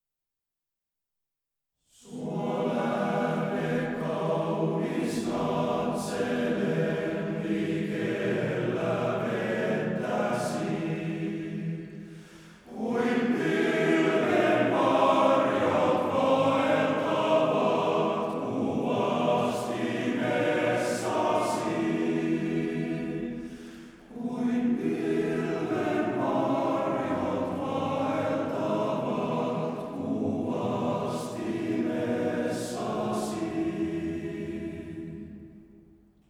Kuoron lauluohjelmisto koostuu mieskuoro-ohjelmiston lisäksi myös erilaisista ja erimaalaisista sotilaslauluista , hengellisistä lauluista ja seranadeista.
Serenadeja ja viihdelauluja